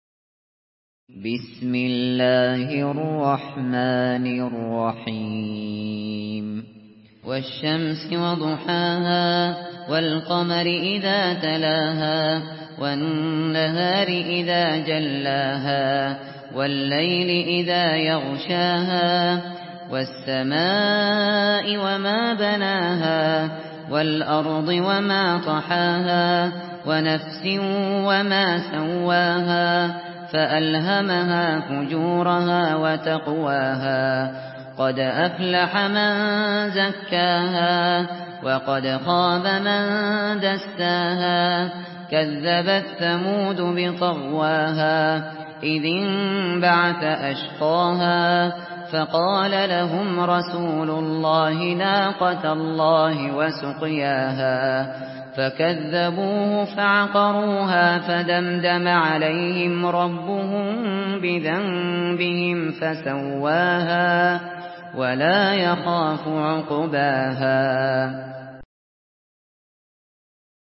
Surah Ash-Shams MP3 by Abu Bakr Al Shatri in Hafs An Asim narration.
Murattal